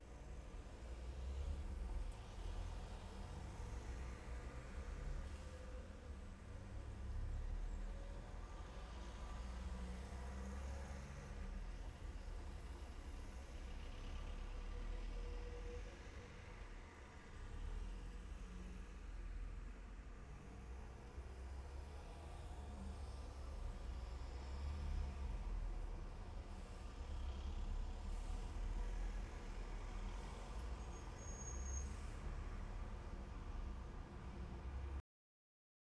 Additionally, it puts the adaptive nature of some ANC systems to the test, as the noise in these tests isn't constant and contains transient sounds like phones ringing and large vehicles accelerating.
You may need to raise your device's volume to distinguish additional details since our output recordings aren't very loud.
Street Noise
street-noise-sample.wav